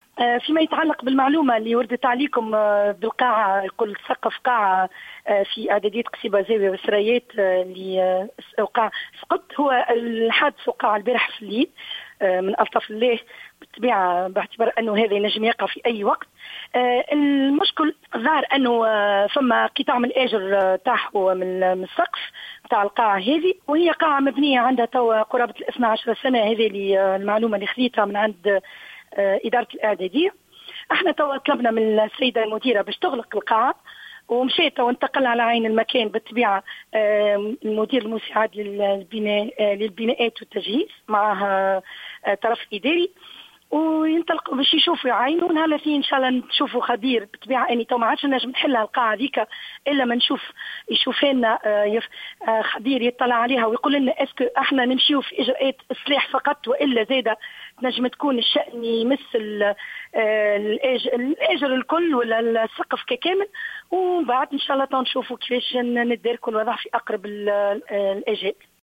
أكدت المندوبة الجهوية للتربية بسوسة، سميرة خضر في تصريح ل"الجوهرة أف أم" سقوط سقف قاعة بالمدرسة الإعدادية الزاوية القصيبة والثريات.